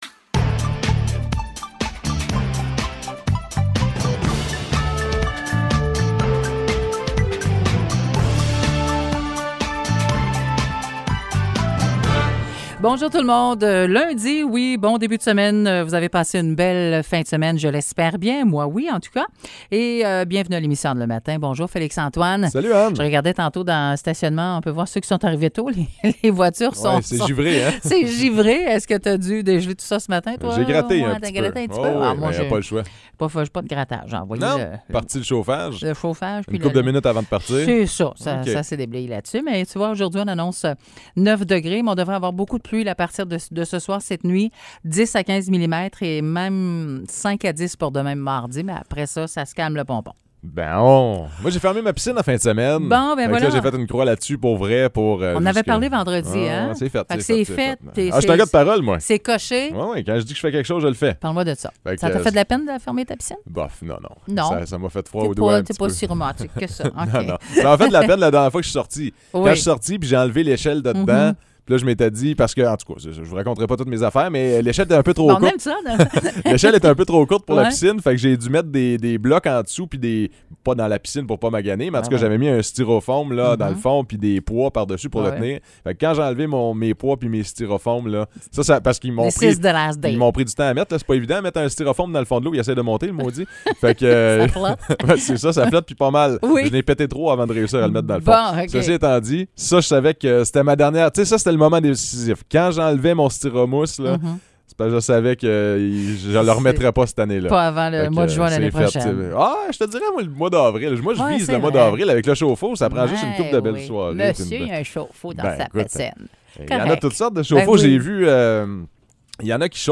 Nouvelles locales - 25 octobre 2021 - 9 h